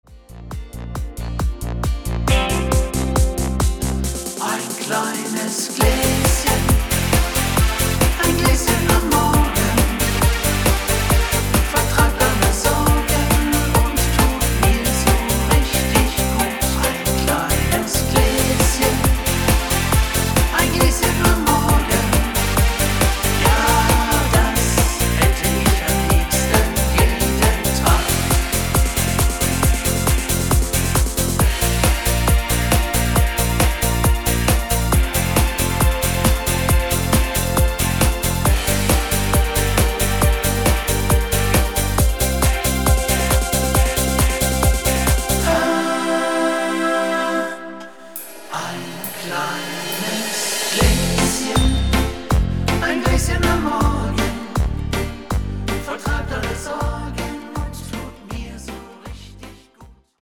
Rhythmus  8 Beat